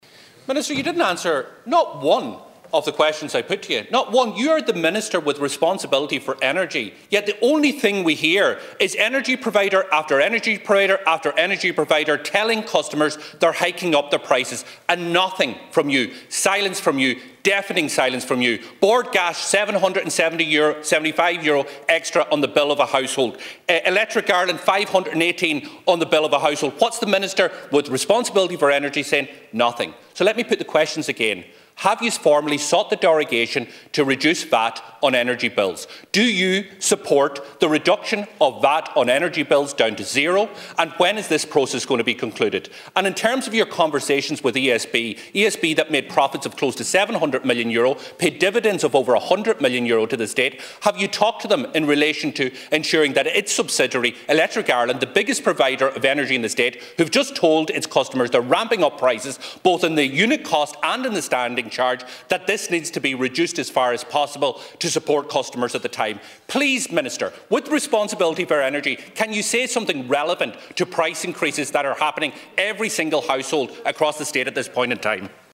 That’s according to Donegal Deputy Pearse Doherty, who has been questioning the Government’s response to the rise in energy prices once again in the Dail this afternoon.